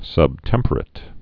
(sŭb-tĕmpər-ĭt, -tĕmprĭt)